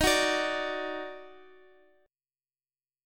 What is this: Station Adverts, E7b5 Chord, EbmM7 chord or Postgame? EbmM7 chord